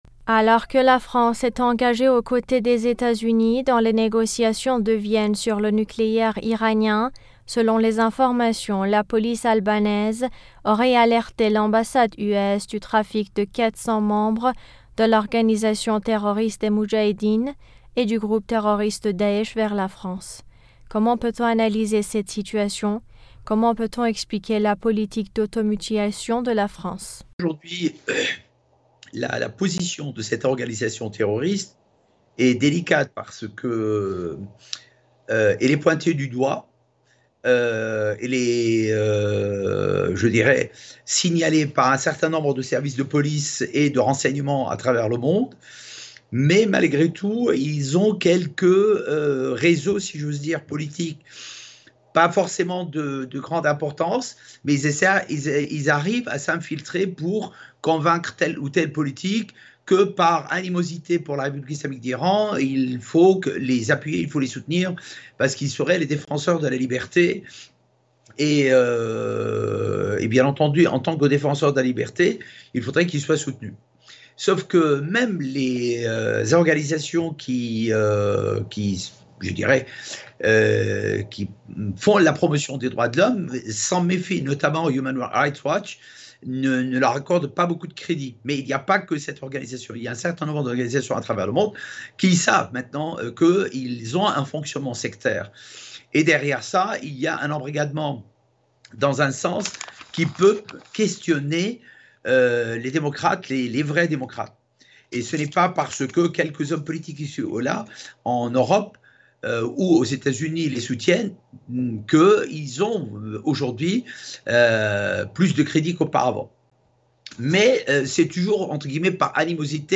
juriste international s’exprime sur le sujet.